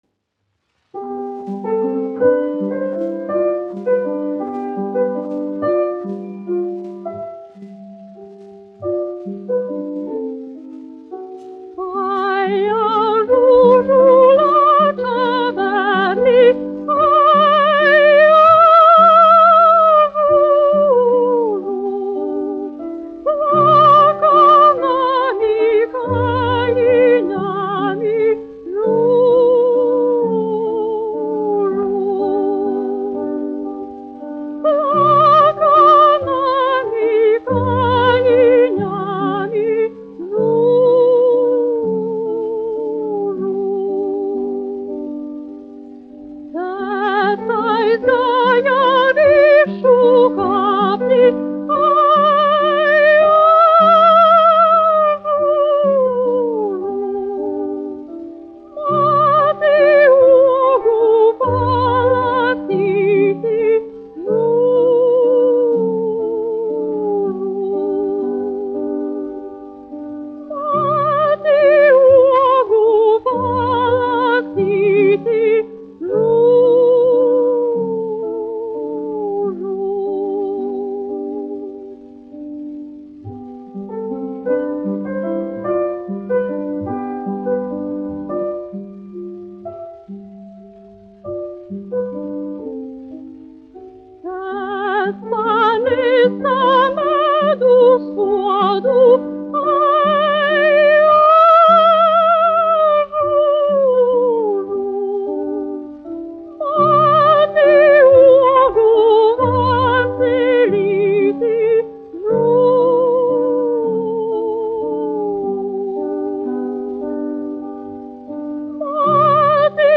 dziedātājs
instrumentālists
1 skpl. : analogs, 78 apgr/min, mono ; 25 cm
Latviešu šūpuļdziesmas
Latviešu tautasdziesmas
Latvijas vēsturiskie šellaka skaņuplašu ieraksti (Kolekcija)